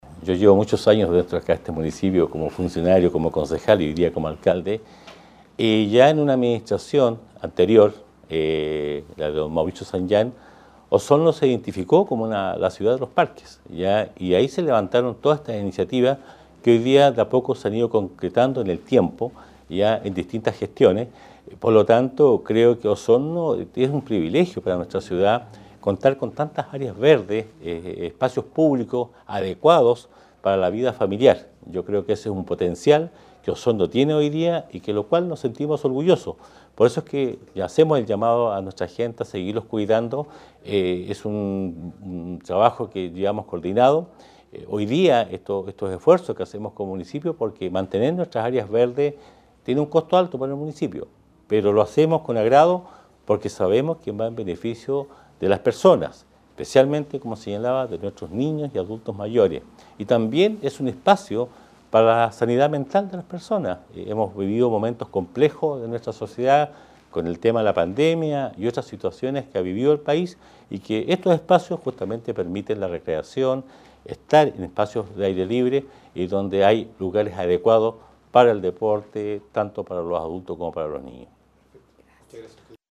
El alcalde osornino señaló que desde hace un par de décadas, Osorno se viene constituyendo como una ciudad Parque, y esa identidad se consolida con el nuevo parque Hott: